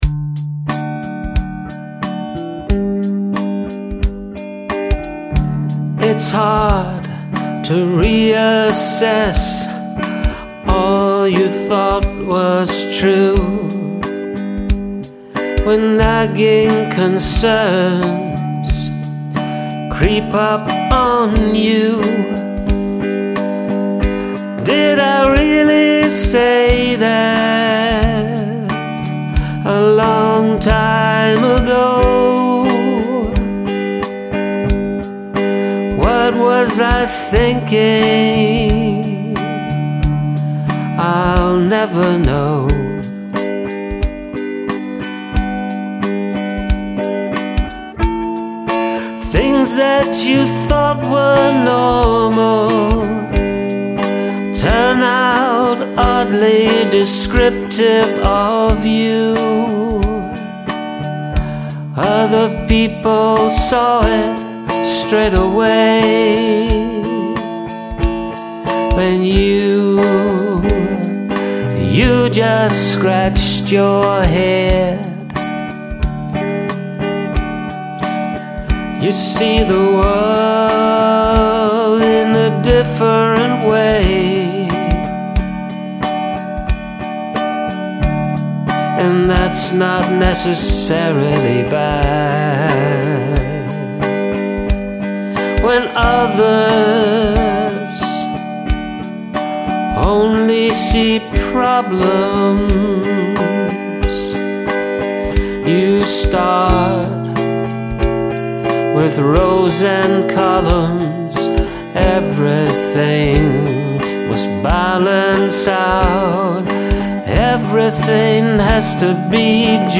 Capo randomly placed on 7!